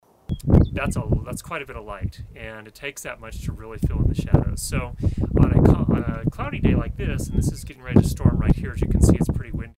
A.24 Wind
lavalier-mic.mp3